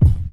• Raw Bass Drum F Key 01.wav
Royality free bass drum single hit tuned to the F note. Loudest frequency: 114Hz
raw-bass-drum-f-key-01-xen.wav